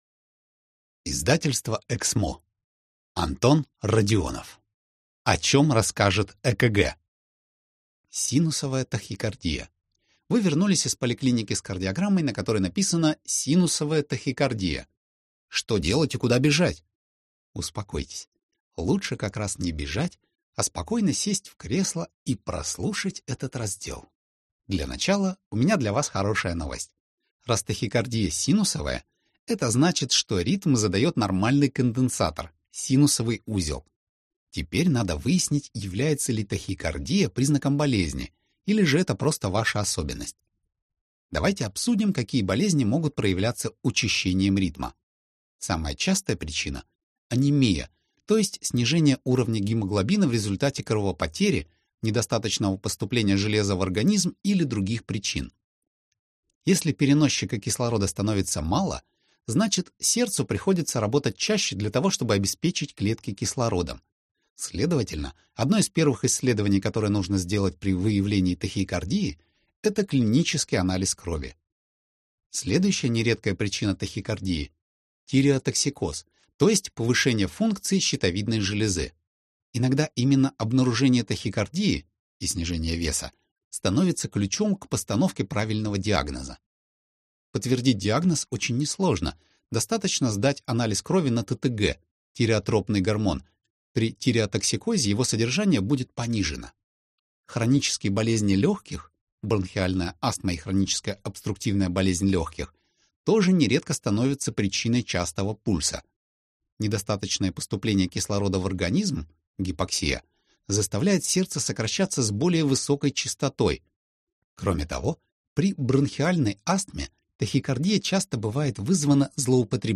Аудиокнига О чем расскажет ЭКГ | Библиотека аудиокниг